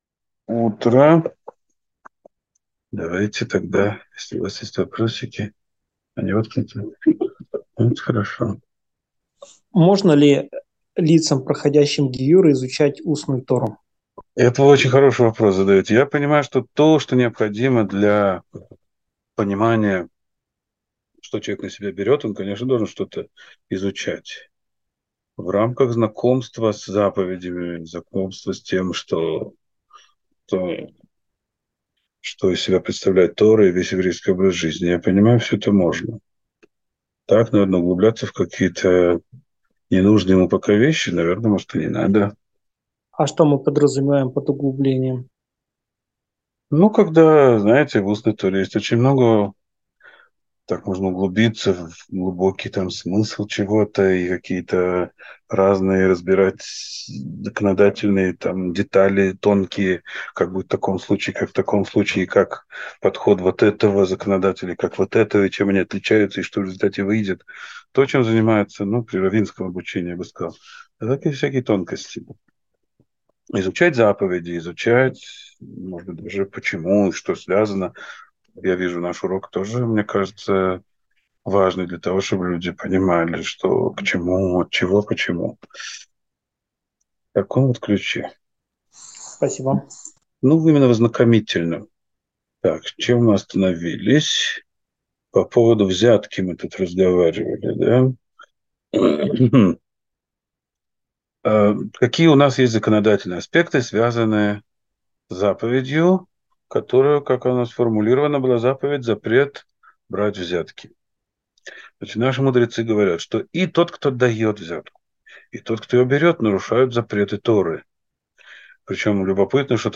Урок 104.